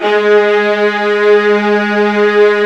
55u-va04-G#2.aif